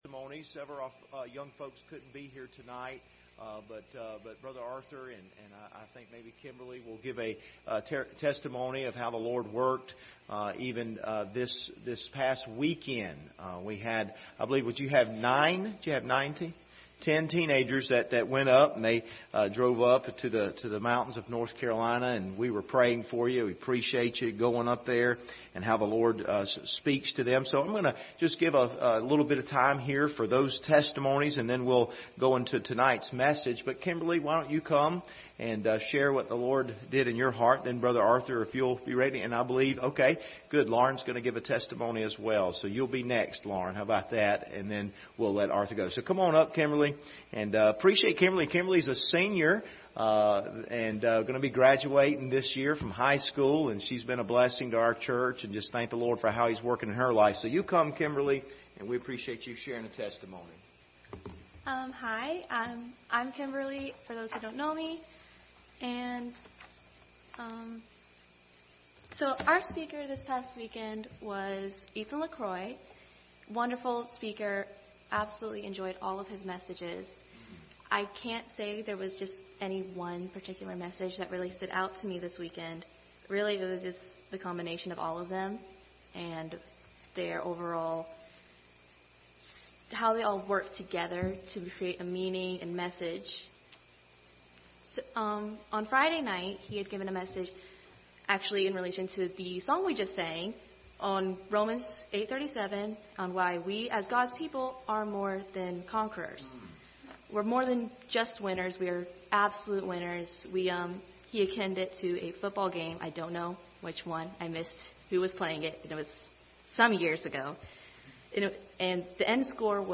Camp Testimonies and Sermon: How to Have Savory Speech
Passage: Colossians 4:5,6 Service Type: Sunday Evening